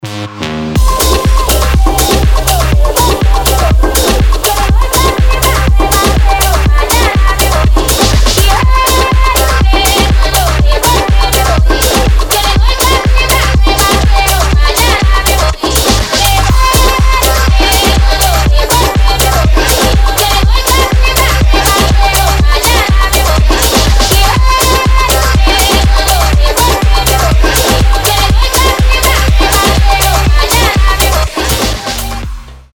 dance
EDM
этнические
Funky House
Jackin House
Groove House
Стиль: FunkyGrooveJackin' house